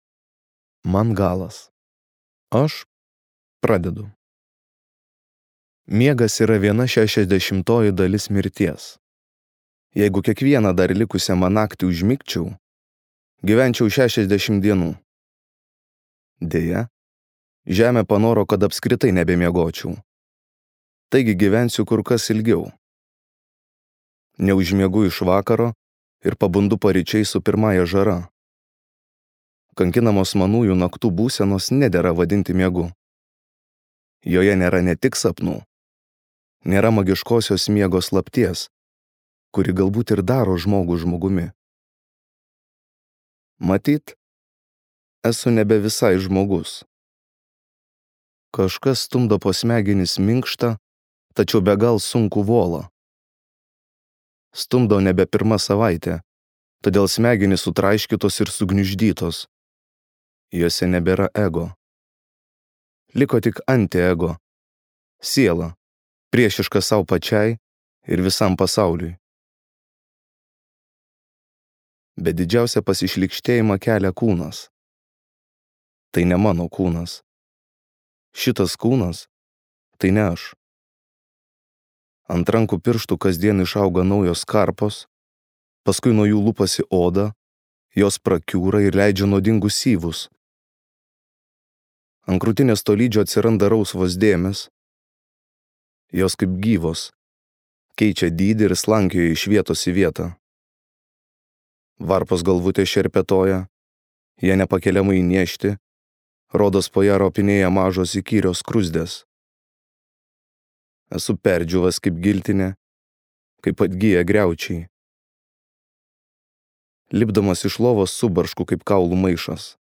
Paskutinioji Žemės žmonių karta | Audioknygos | baltos lankos